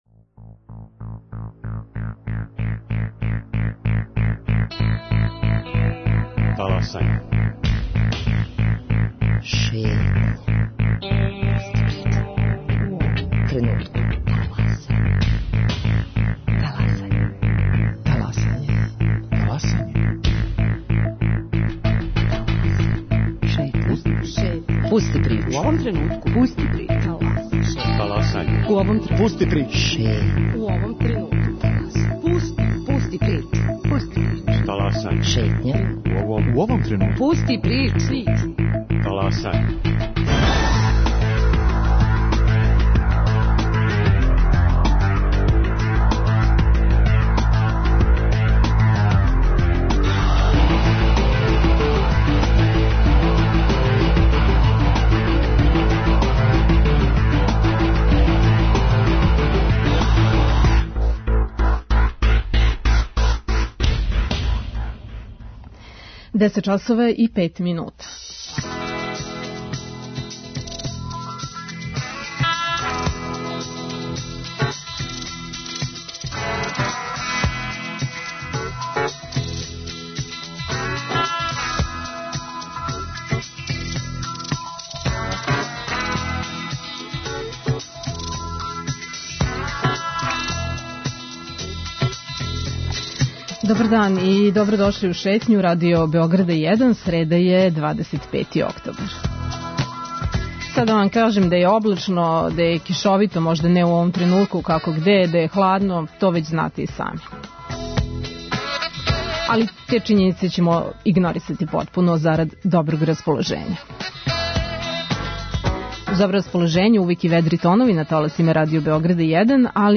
О преквалификцијама за ИТ сектор и обуци за јуниор програмере разговарамо са директором Канцеларије за информационе технологије Владе Србије Михаилом Јовановићем.